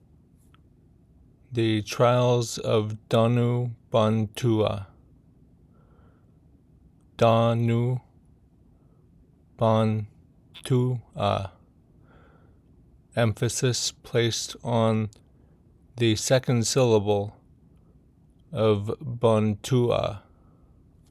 Pronunciation Guide
danu-bantuah-pronunciation.m4a